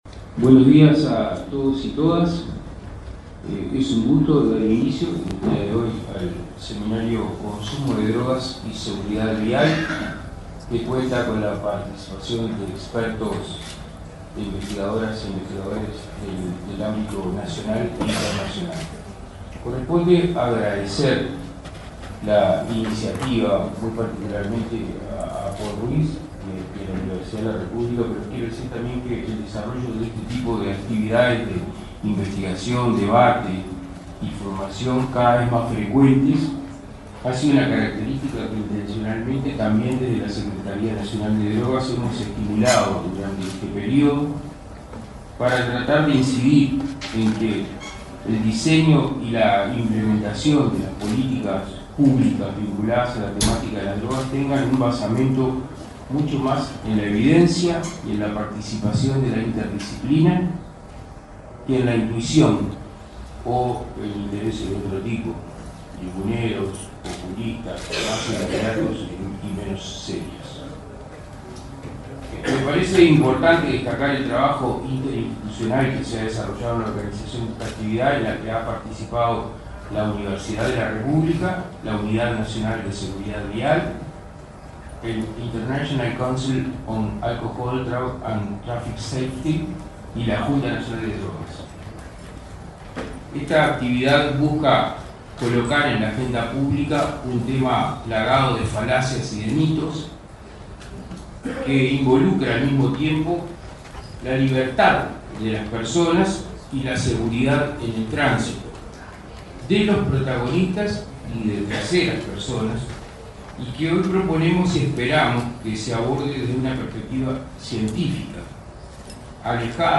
Palabras de autoridades en acto en la Torre Ejecutiva
Este lunes 2 en la Torre Ejecutiva, el secretario de Drogas, Daniel Radío, y el presidente de la Unidad Nacional de Seguridad Vial, Alejandro Draper,